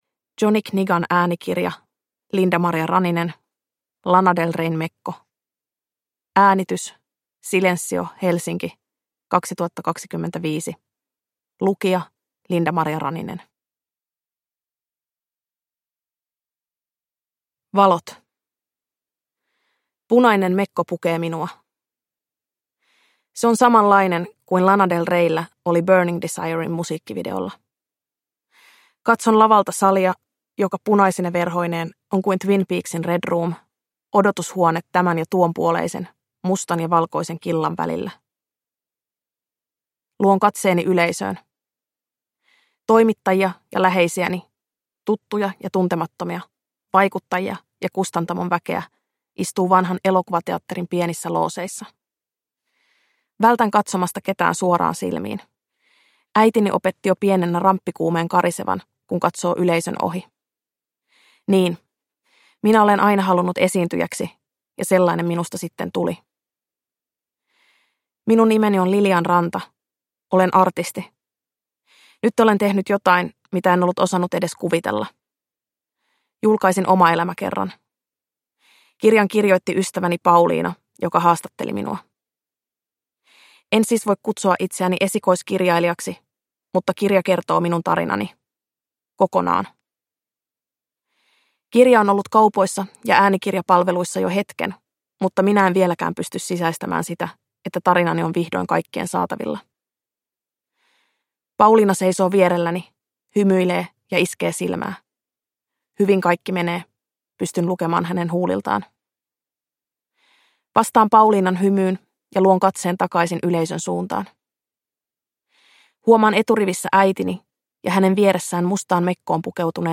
Lana Del Reyn mekko – Ljudbok